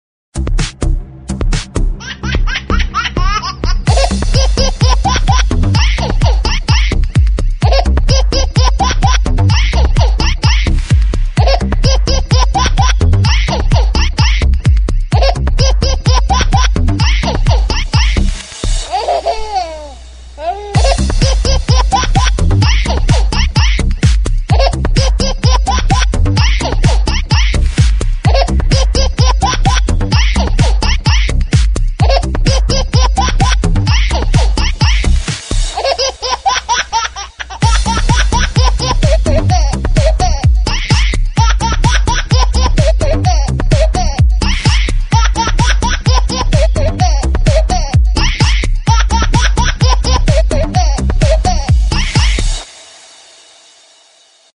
• Качество: 48, Stereo
смешные
еще один вариант рингтона с детским смехом)